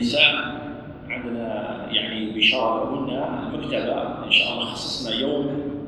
It’s clearer.